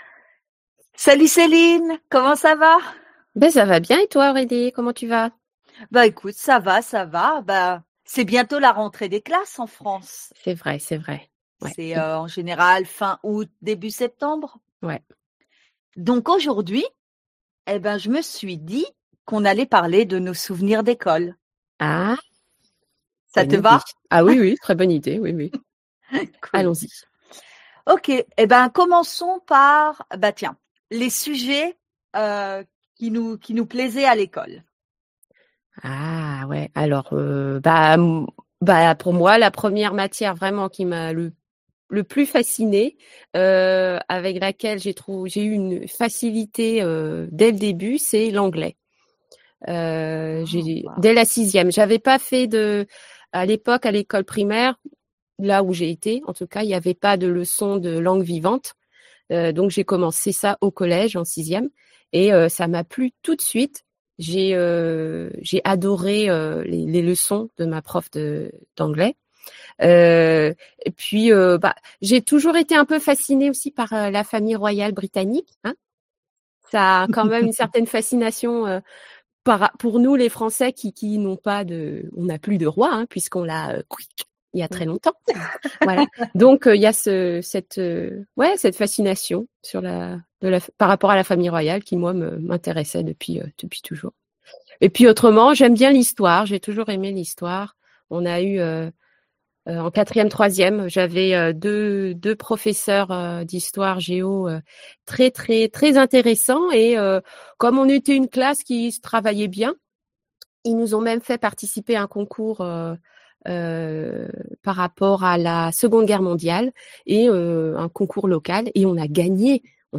French Conversation Podcast